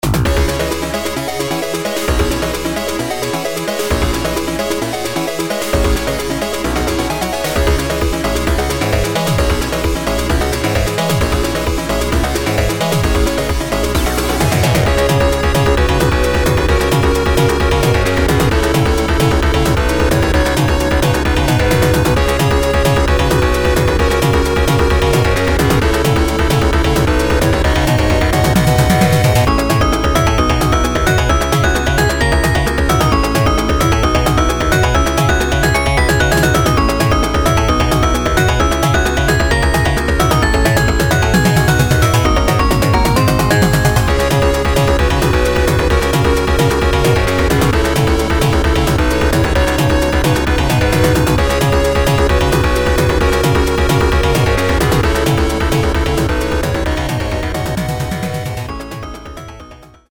ドラムパートをのぞけばメロディーはほとんどいじってません。